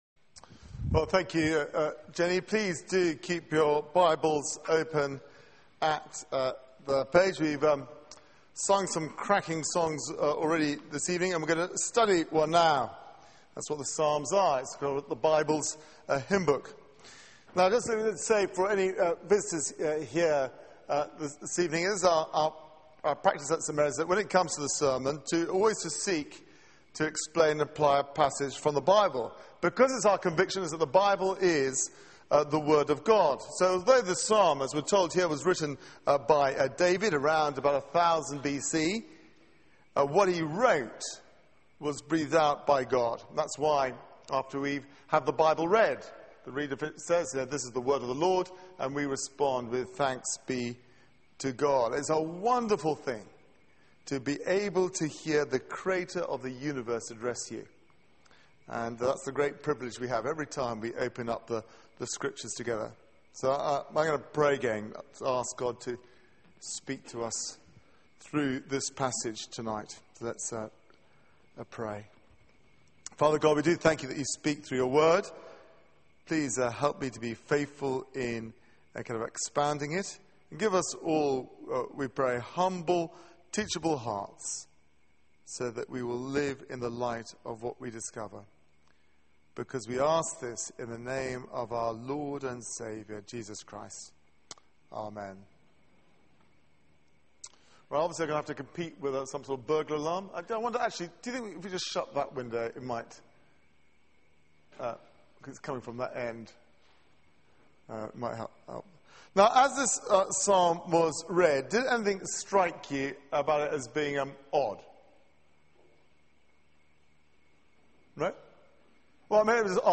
Media for 6:30pm Service on Sun 12th Aug 2012 18:30 Speaker
Series: Summer Songs Theme: Why atheists are fools Sermon Search the media library There are recordings here going back several years.